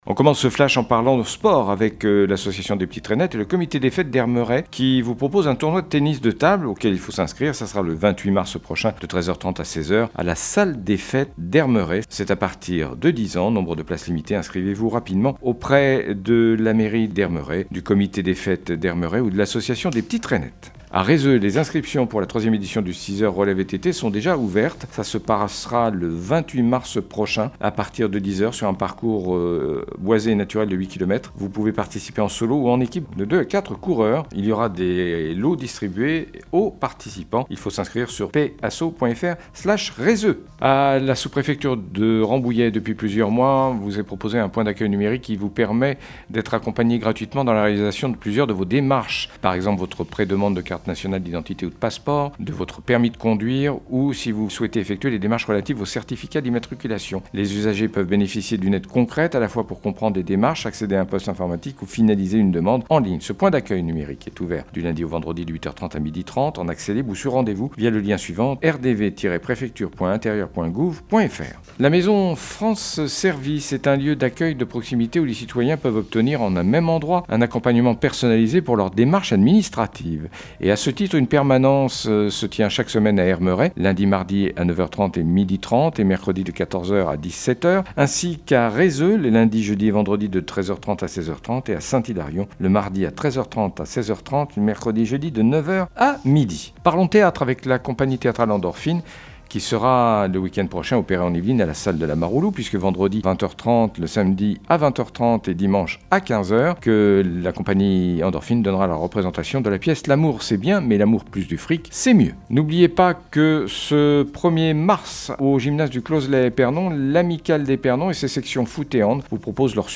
Le journal local